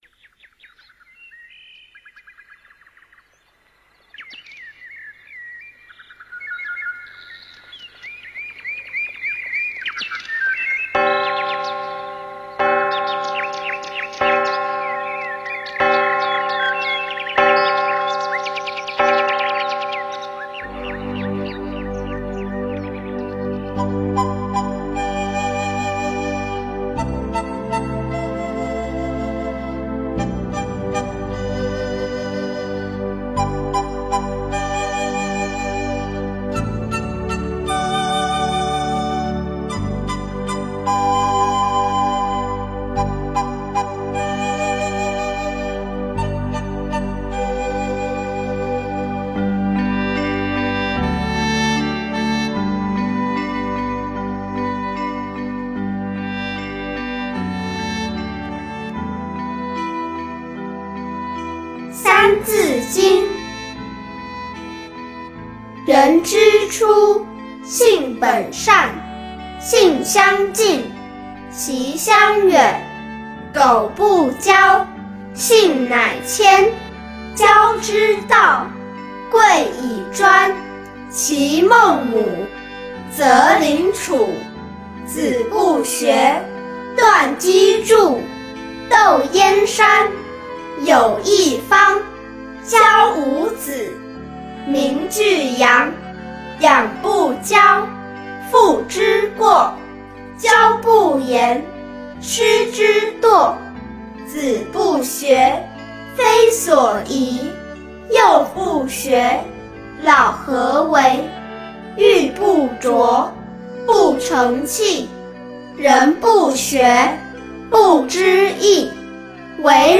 三字经--新韵传音 经忏 三字经--新韵传音 点我： 标签: 佛音 经忏 佛教音乐 返回列表 上一篇： 南无阿弥陀佛(弥陀颂)--新韵传音 下一篇： 礼88佛大忏悔文--佚名 相关文章 南无般若会上佛菩萨--如是我闻 南无般若会上佛菩萨--如是我闻...